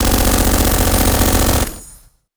ZombieSkill_SFX
sfx_skill 04.wav